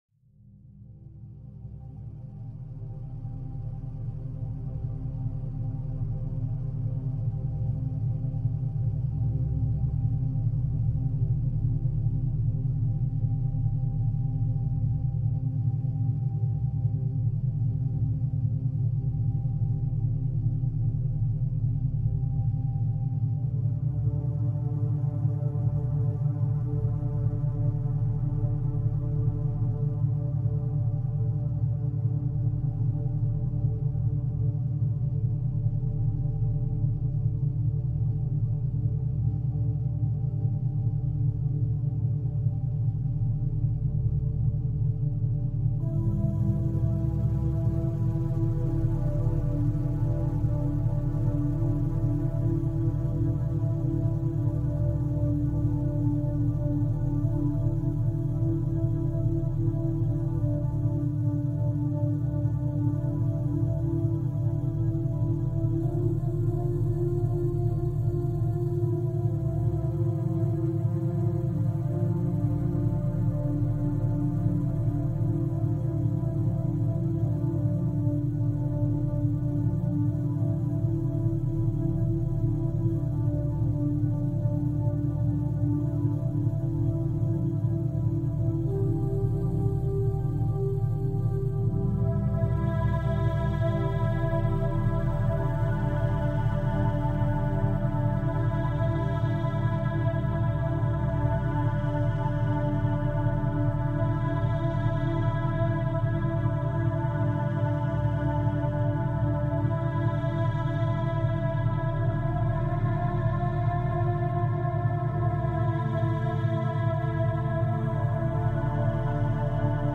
Pluie douce en 528 Hz · méthode scientifique 2 heures productives